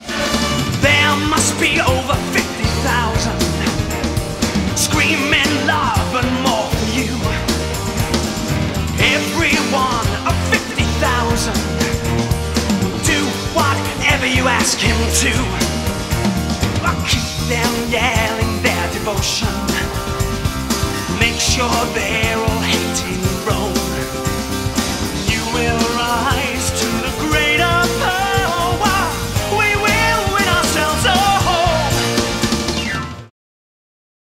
рок-оперы